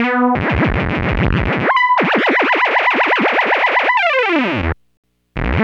Synth 26.wav